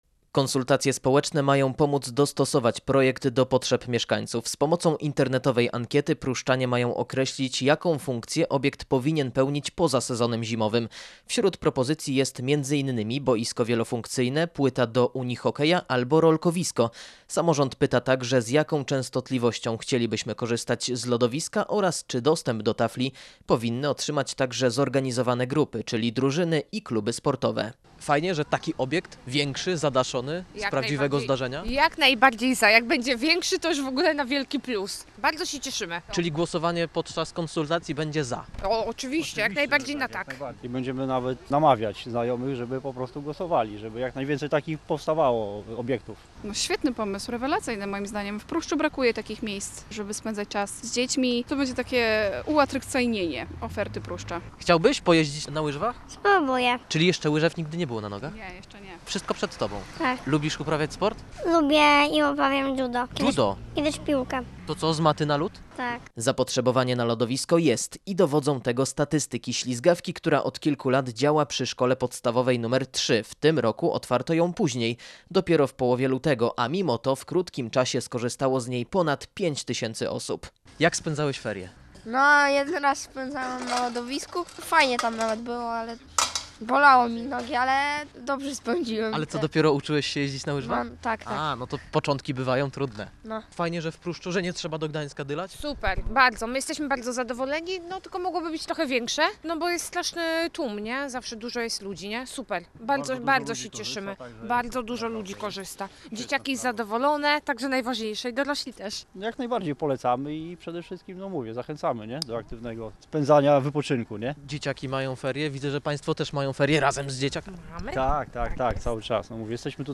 Powinno powstawać więcej takich obiektów – mówili Radiu Gdańsk mieszkańcy Pruszcza Gdańskiego korzystający z miejskiej ślizgawki przy SP nr3.